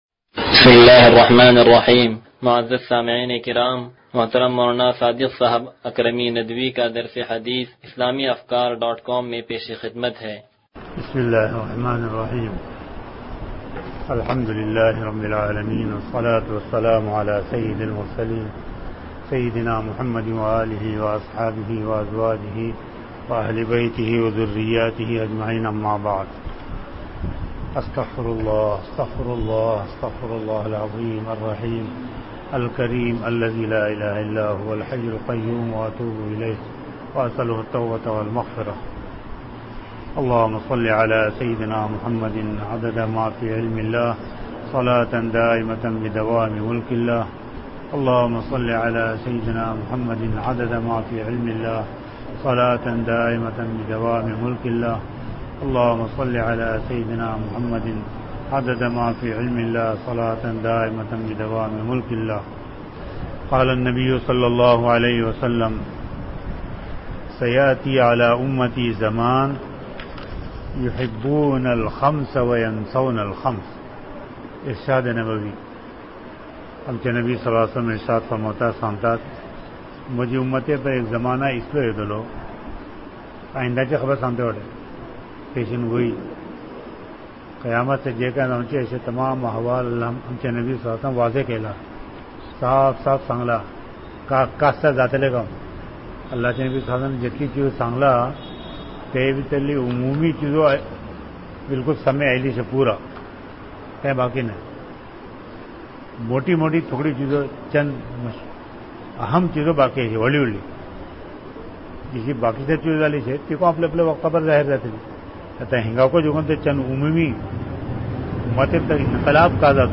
درس حدیث نمبر 0135